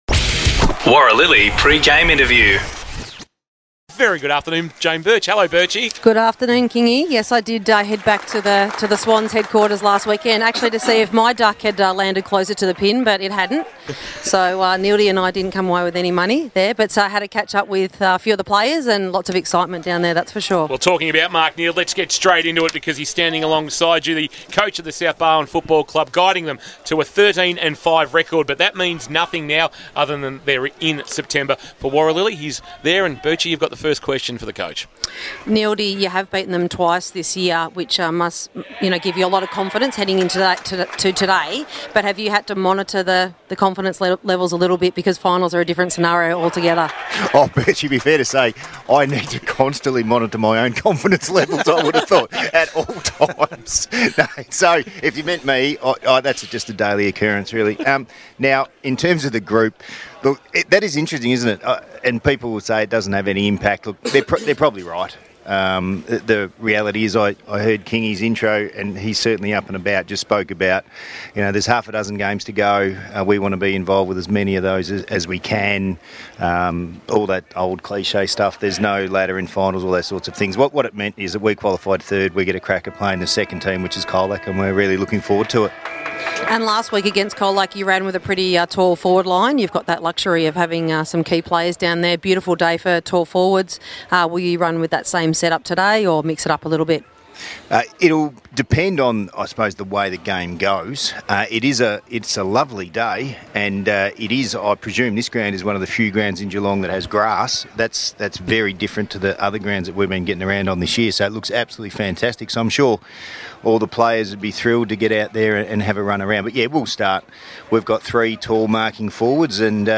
2023 - GFNL - Qualifying Final - COLAC vs. SOUTH BARWON: Pre-match interview - Mark Neeld (South Barwon coach)